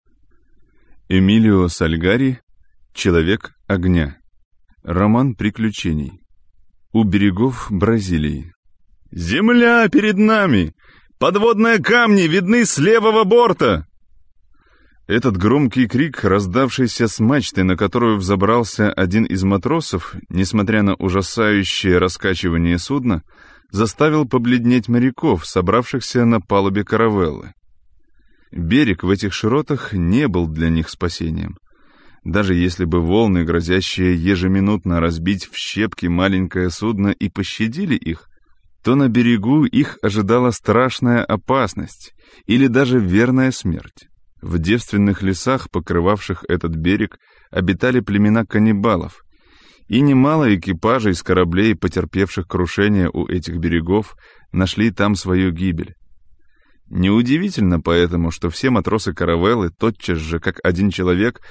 Аудиокнига Человек огня - Сальгари Эмилио - Скачать книгу, слушать онлайн
Скачать, слушать онлайн аудиокнигу Человек огня автора Сальгари Эмилио